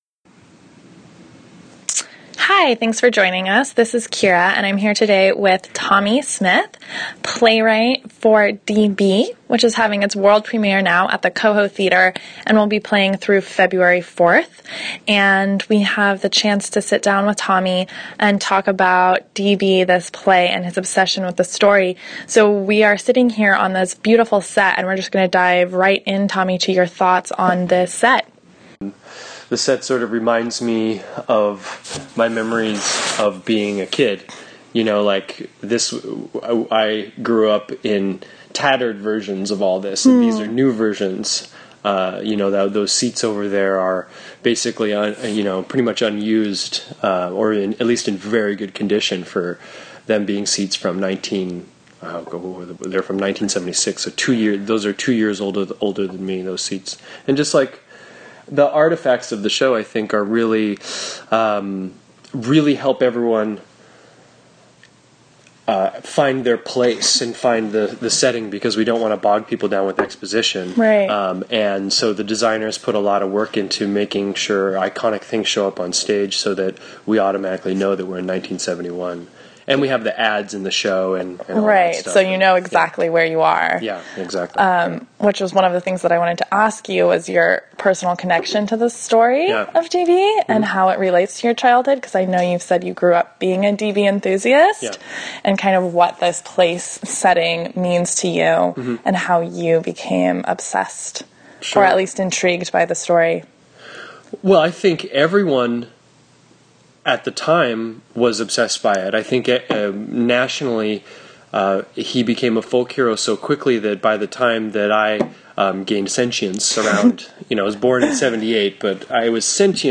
Click below to listen to the interview, then come see the play, running at the CoHo Theatre through February 4th.